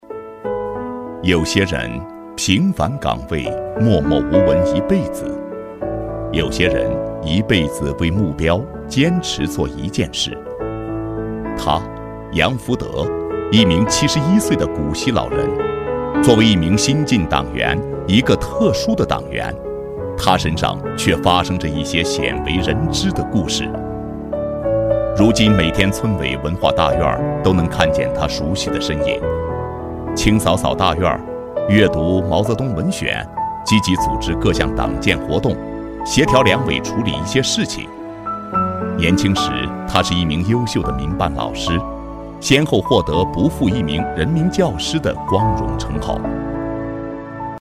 人物男10号
中年男音，特点，偏老陈，磁性，稳重，自然，激情。